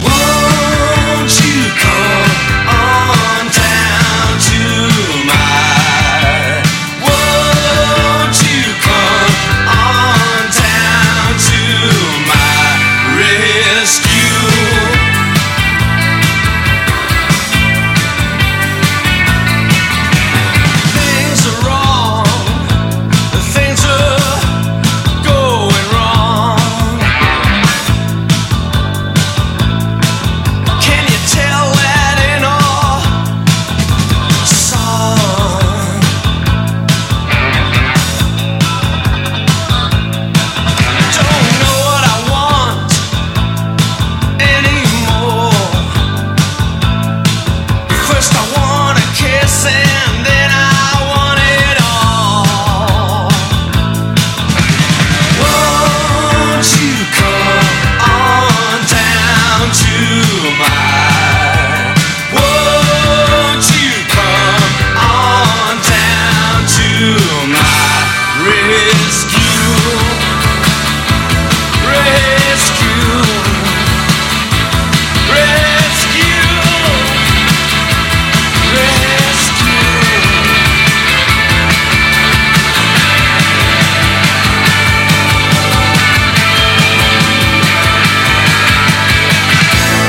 NEO-ACO/GUITAR POP / INDIE DANCE